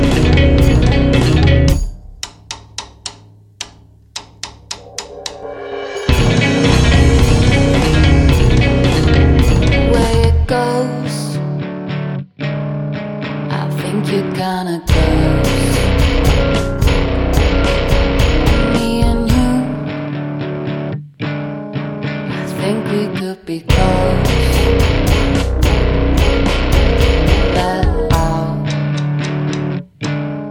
De retour en formation trio